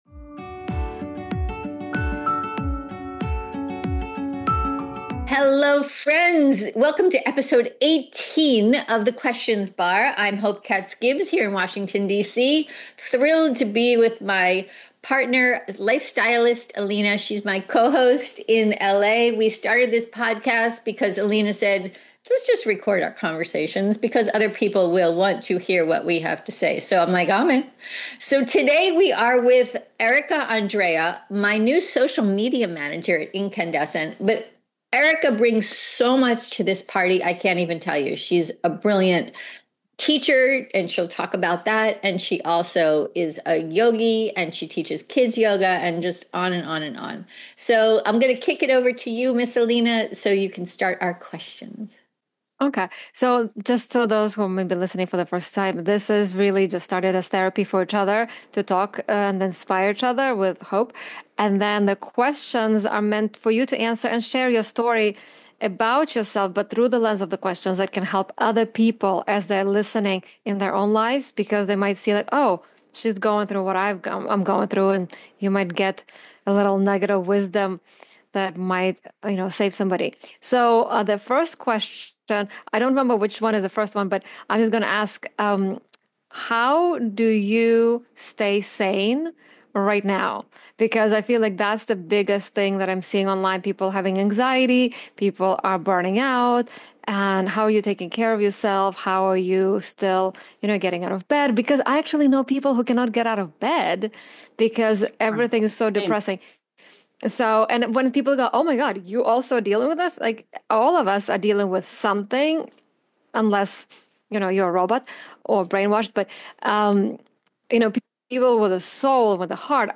Today on the Questions Bar: Check out Ep18 of the show where we interview a woman who teaches that inner-knowing, and not taking shit, guides powerful women to live the life of their dreams!